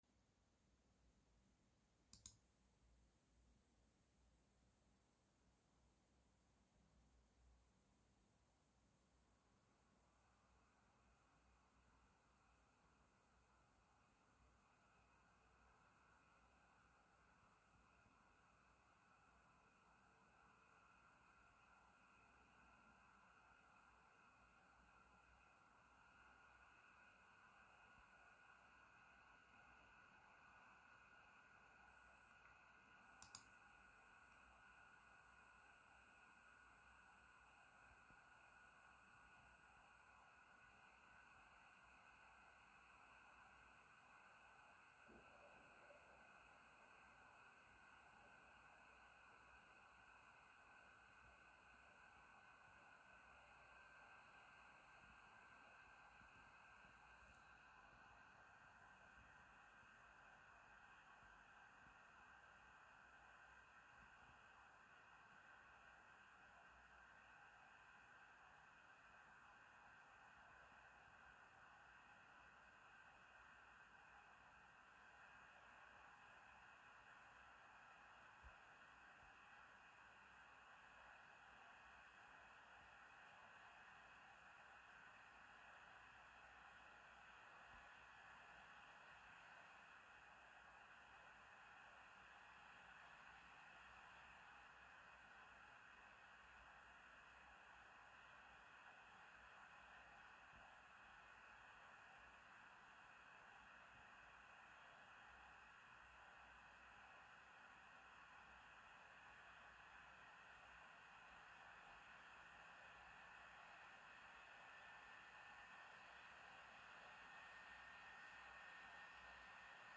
Um die Geräuschkulisse besser zu dokumentieren finden sich nachfolgend drei Tonaufnahmen, die den Flüstermodus, das Standardprofil und den Leistungsmodus abbilden. Die Aufnahme erfolgte mit 40 Zentimetern zur Gehäusefront aus dem Leerlauf in einen CPU-Volllast-Benchmark.
Dadurch ändert sich auch das Geräusch, was mitunter als nervig empfunden werden kann.